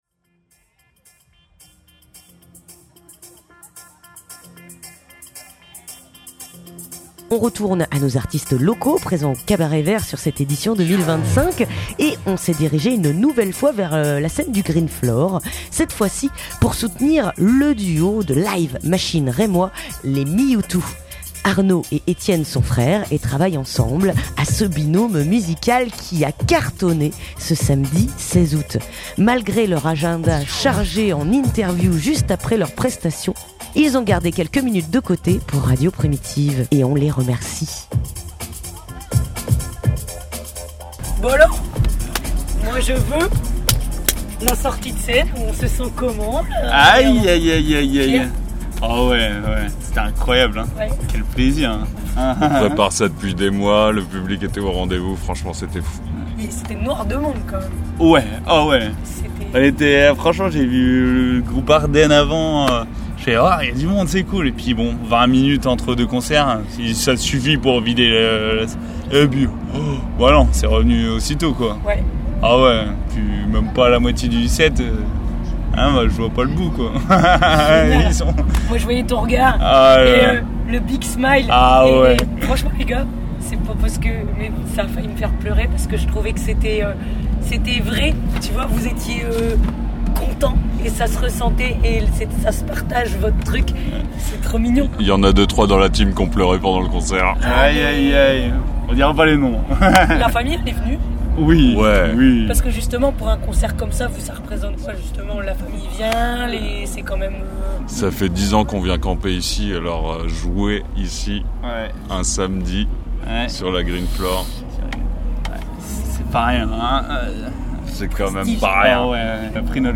Le duo Rémois Meotew nous accorde quelques minutes en sortie de scène de leur concert au Greenfloor. Ils parlent à Radio Primitive de cette expérience d'artiste après avoir été festivaliers de nombreuses années, mais aussi de leurs envies futures.